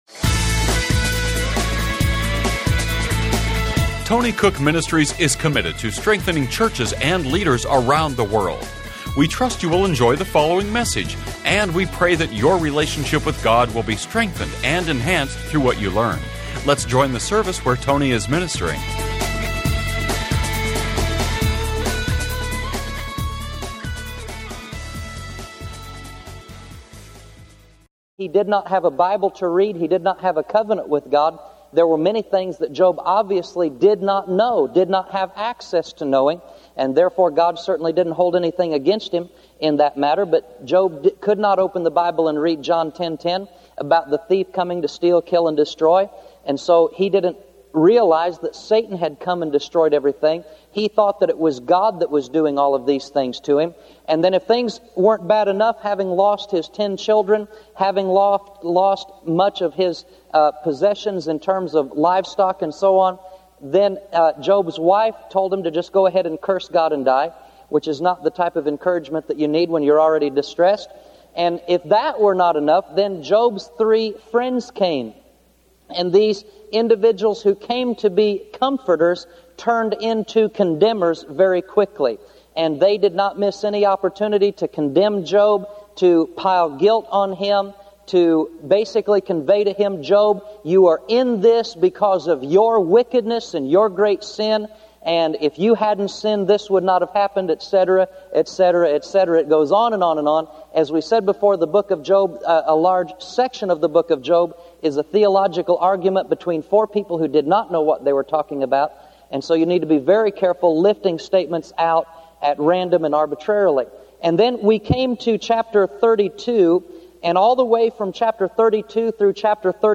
Genre: Christian Teaching.